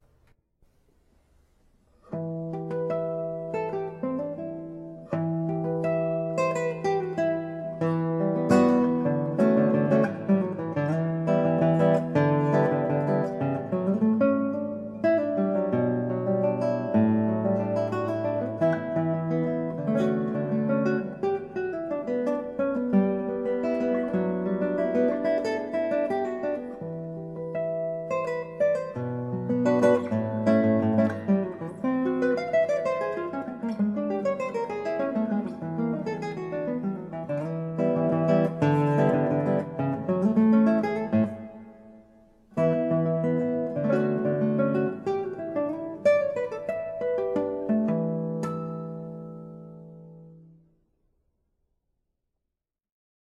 KOMPOSITIONEN FÜR  GITARRE  SOLO